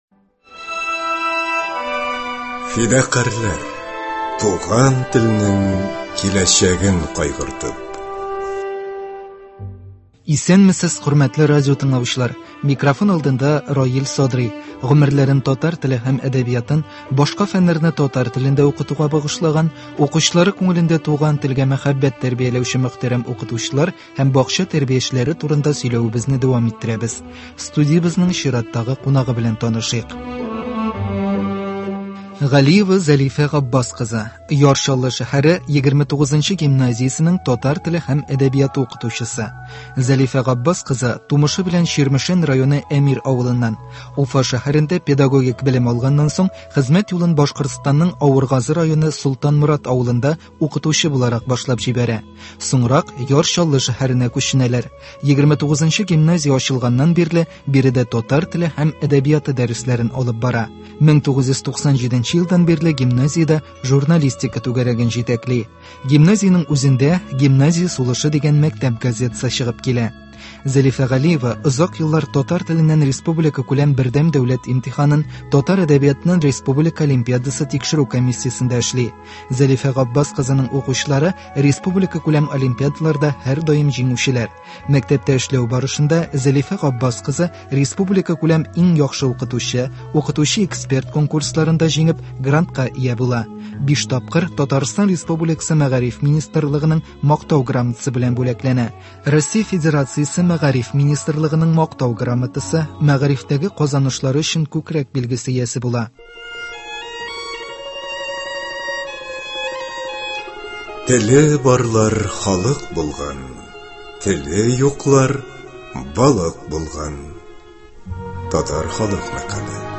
Гомерләрен татар теле һәм әдәбиятын, башка фәннәрне татар телендә укытуга багышлаган, укучылары күңелендә туган телгә мәхәббәт тәрбияләүче мөхтәрәм укытучылар һәм бакча тәрбиячеләре турында сөйләвебезне дәвам иттерәбез. Студиябезнең чираттагы кунагы